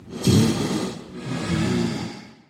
Minecraft / mob / blaze / breathe4.ogg
breathe4.ogg